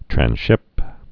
(trăn-shĭp)